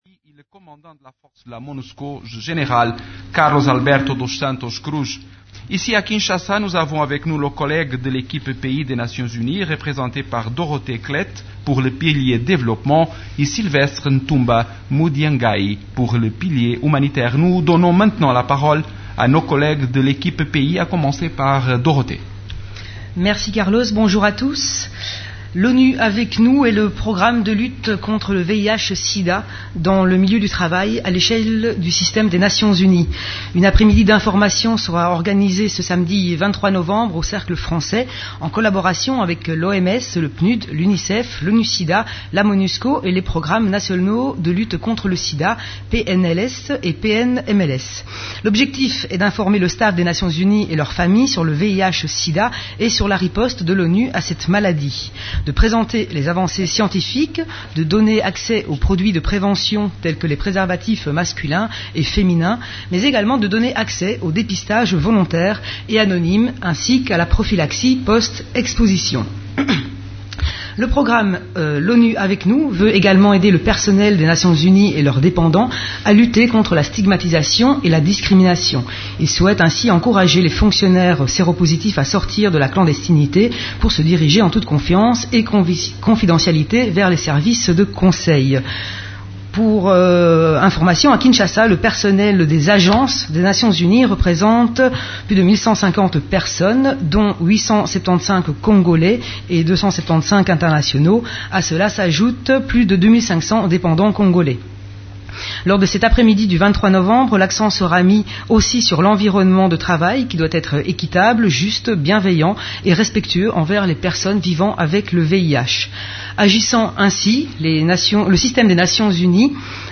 Conférence hebdomadaire des Nations unies du mercredi 20 novembre